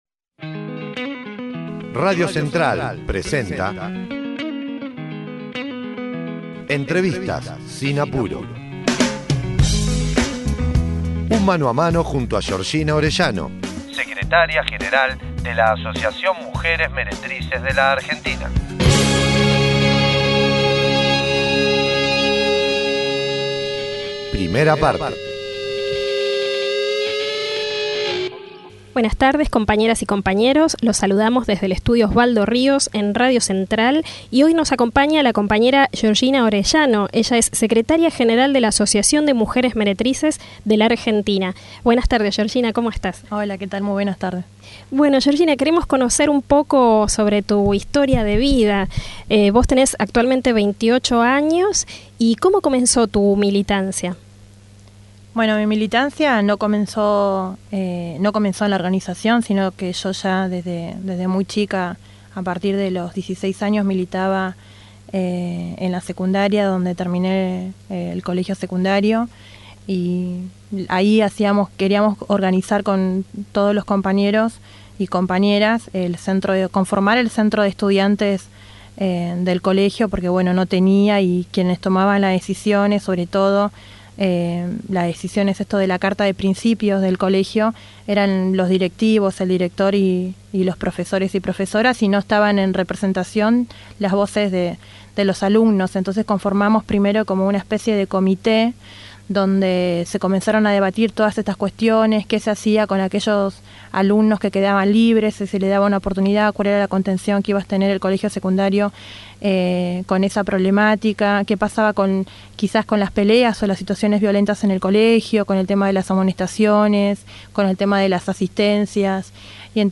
RADIO CENTRAL, presenta: "Entrevistas sin apuro"